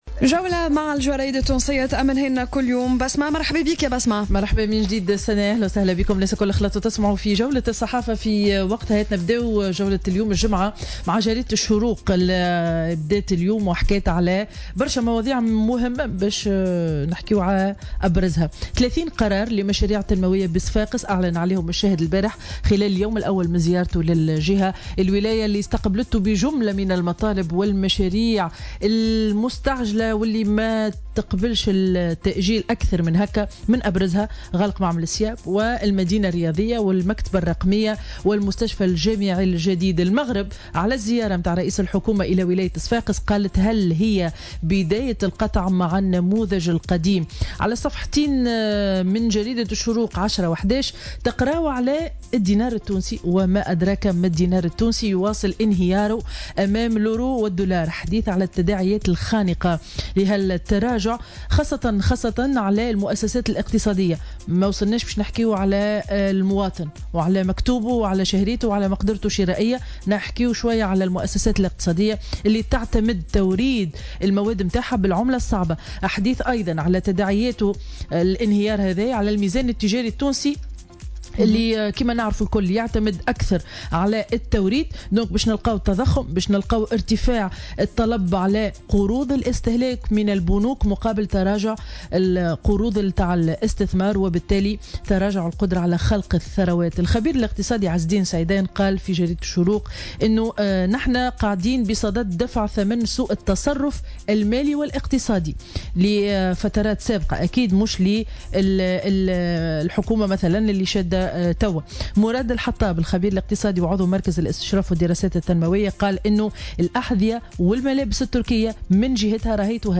Revue de presse du vendredi 21 avril 2017